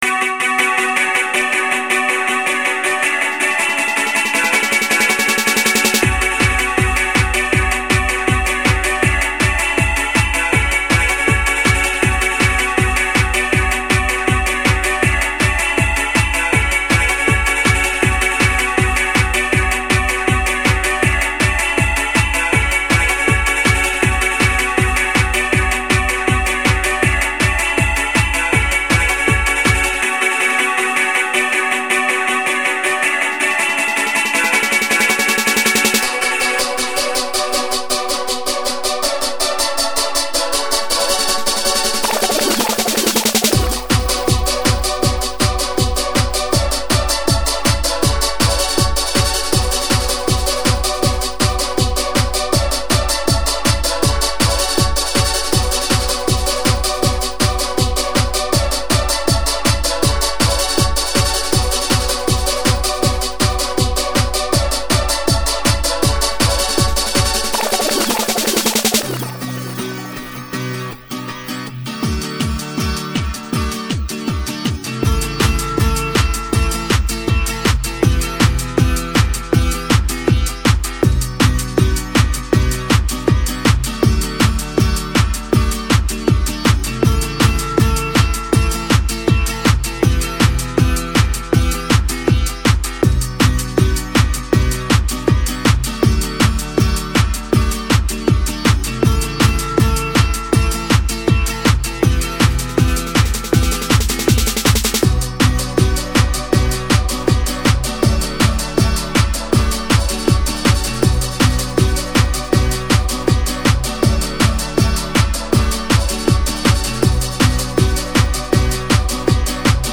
dance/electronic
House
Techno
Hardcore